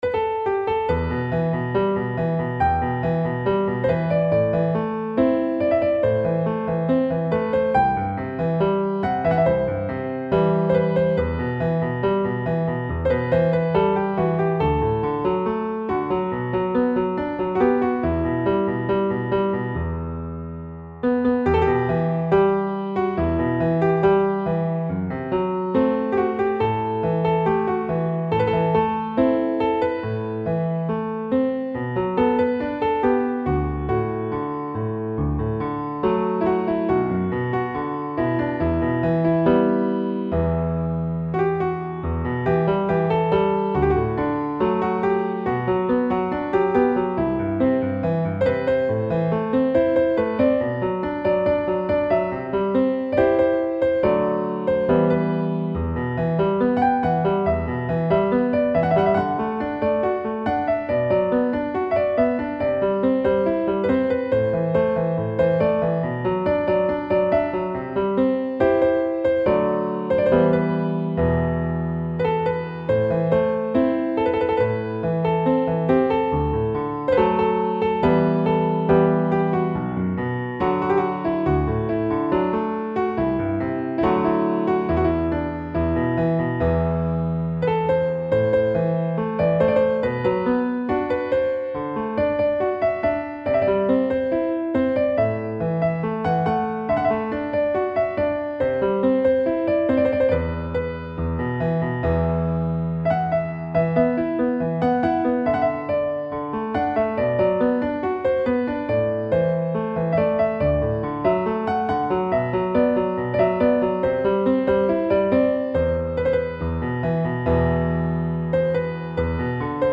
پیانو